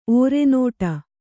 ♪ ōrenōṭa